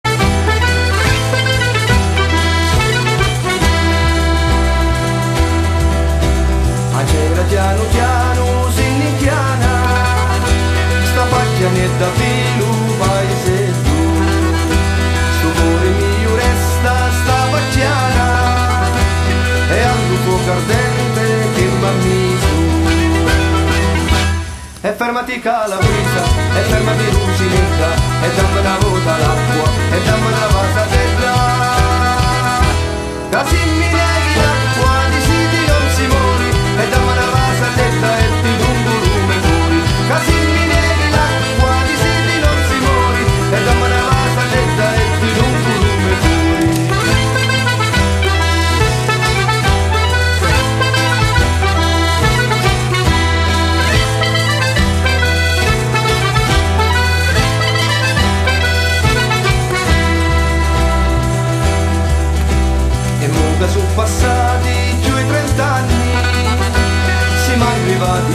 Voce e Chitarra
Fisarmonica
Percussioni